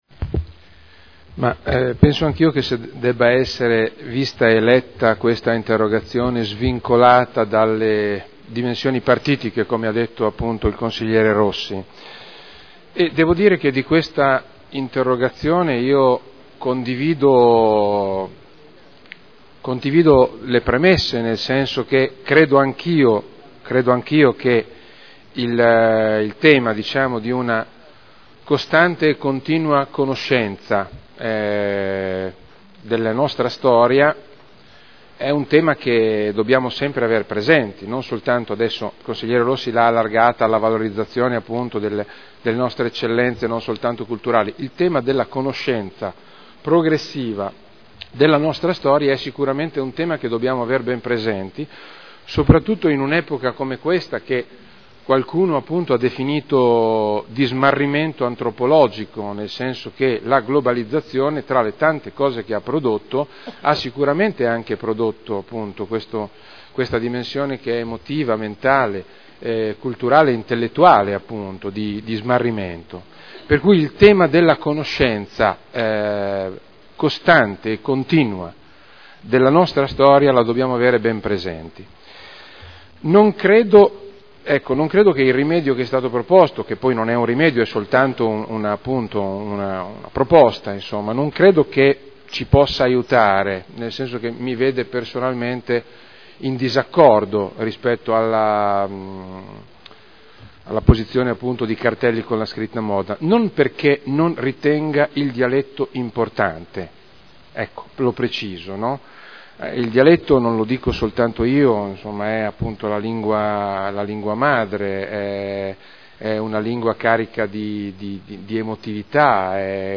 Roberto Alperoli — Sito Audio Consiglio Comunale
Risponde a interrogazione del consigliere Rossi N. (Lega Nord) avente per oggetto: “Cartelli “Mòdna””